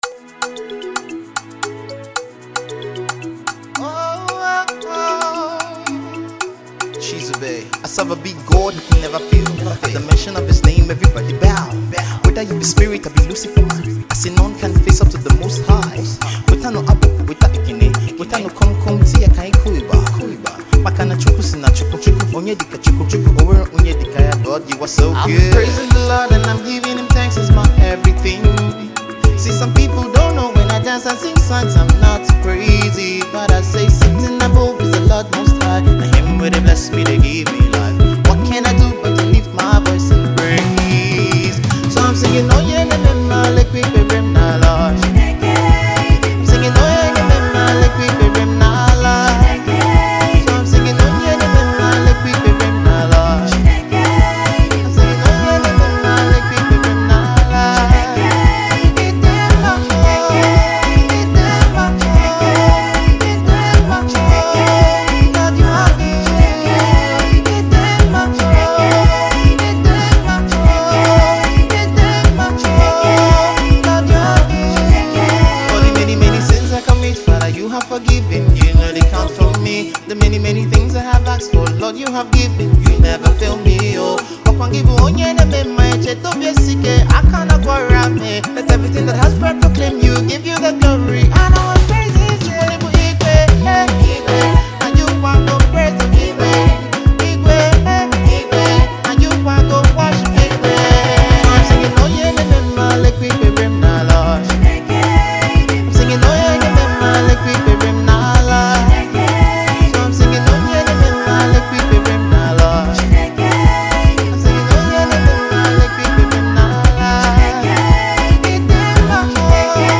a song of Praise and thanksgiving to God